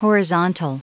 Prononciation du mot horizontal en anglais (fichier audio)
Prononciation du mot : horizontal